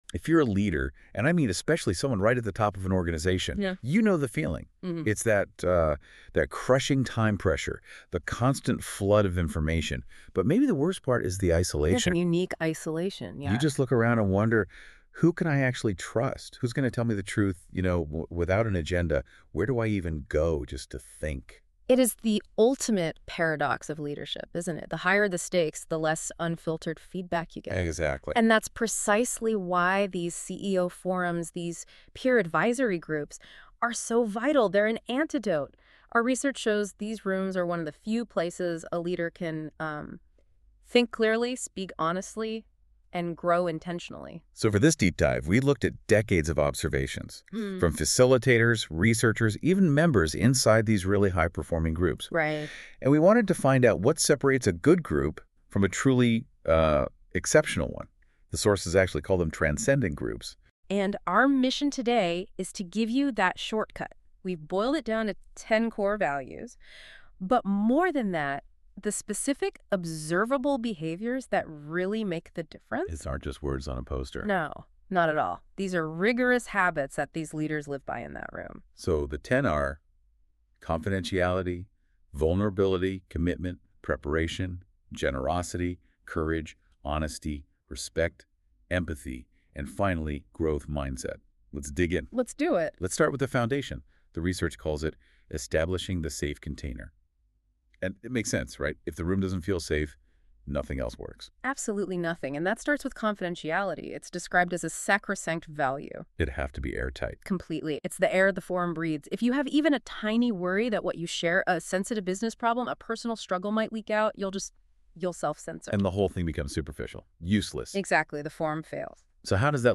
and don't miss DwAIne & JAIne's Peernovation Deep Dive, powered by Google's NotebookLM, where they unpack the values and behaviors that separate the best CEO Forums from the rest.